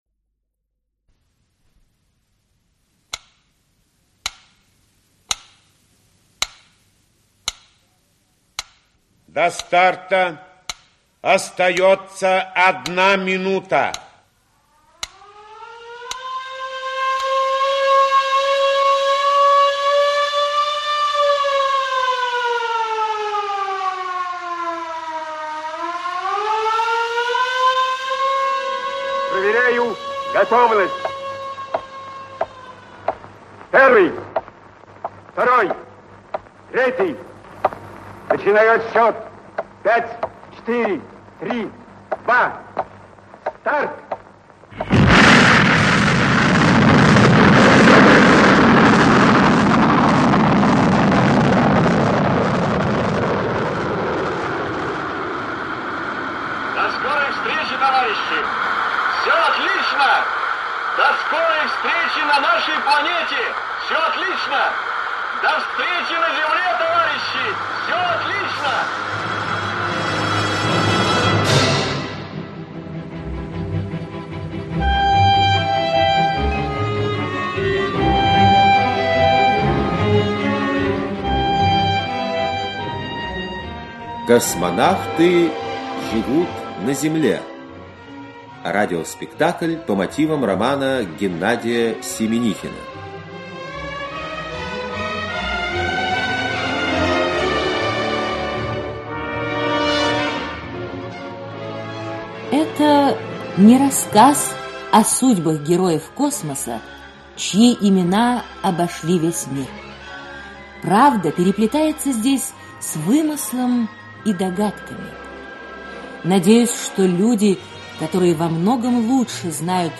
Aудиокнига Космонавты живут на земле Автор Геннадий Александрович Семенихин Читает аудиокнигу Олег Табаков.